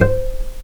vc_pz-C5-pp.AIF